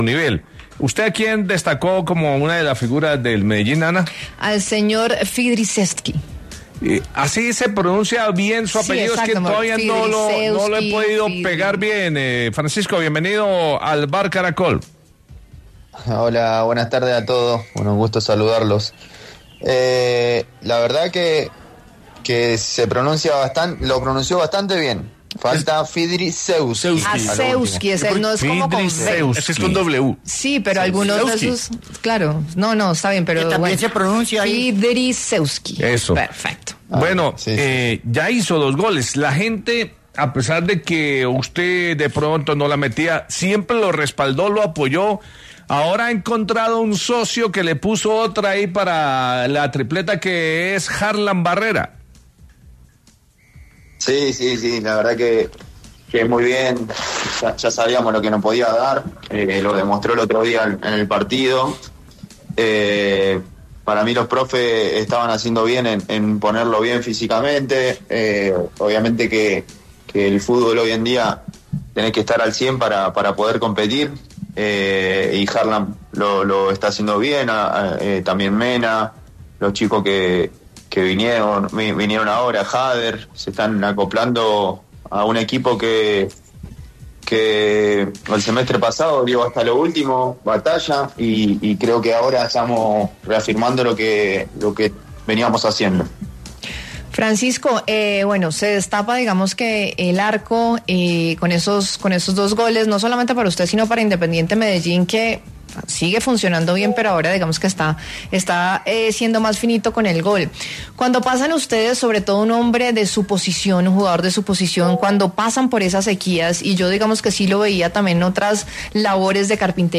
En diálogo con El VBar Caracol, el delantero de 32 años se refirió, entretanto, a cómo se levantó del golpe anímico que significó la pérdida de la final de la Liga ante Santa Fe.